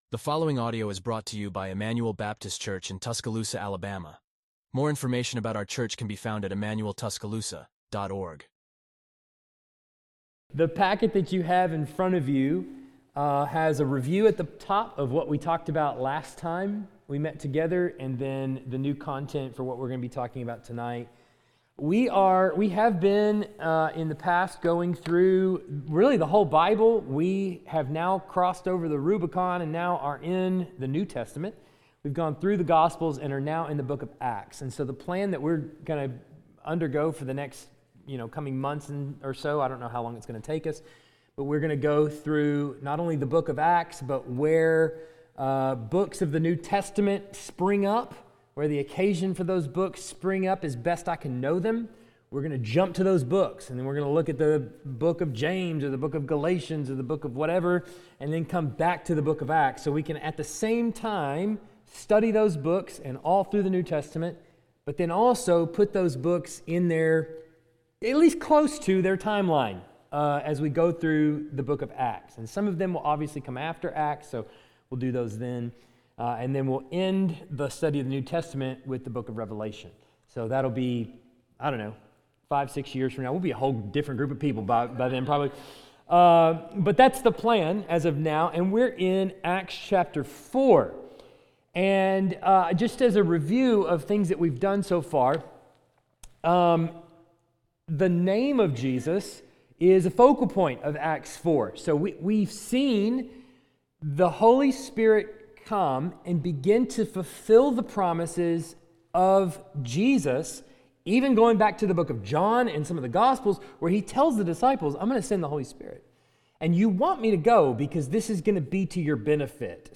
Wednesday evening Bible Study